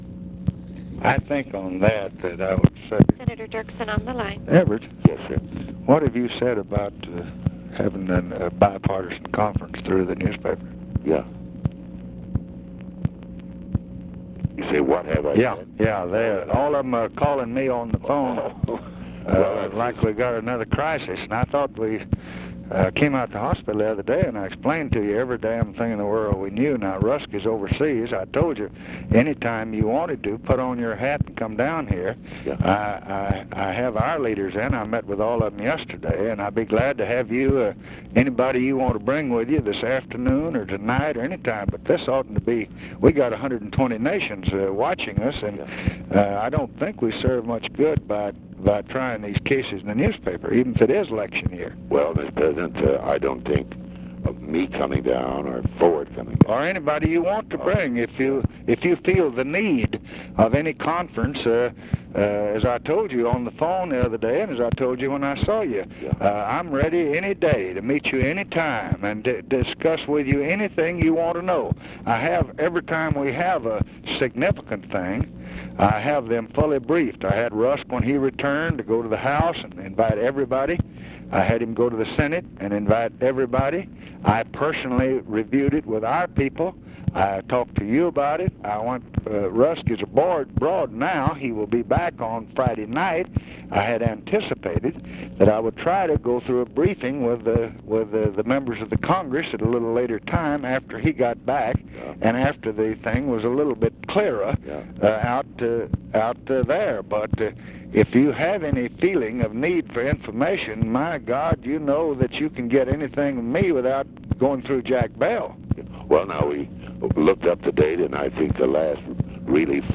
Conversation with EVERETT DIRKSEN and OFFICE CONVERSATION, June 9, 1966
Secret White House Tapes